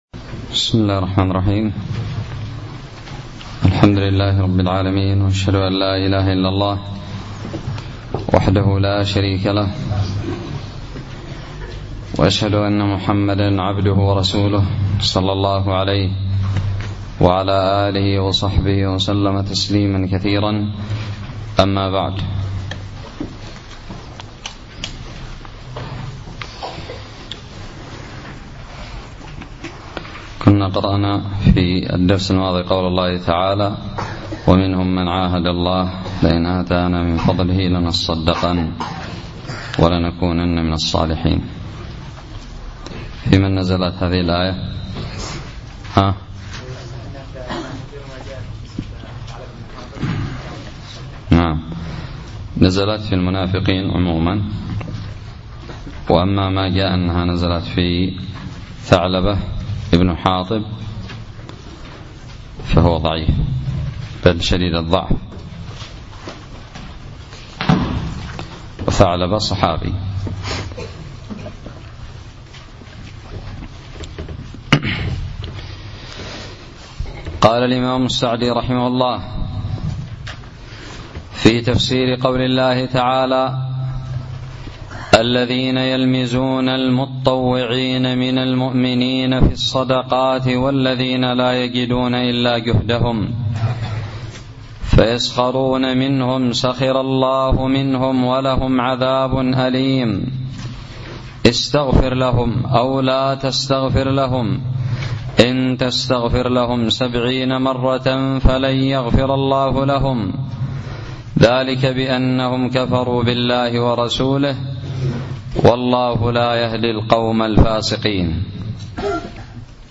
الدرس الخامس والثلاثون من تفسير سورة التوبة
ألقيت بدار الحديث السلفية للعلوم الشرعية بالضالع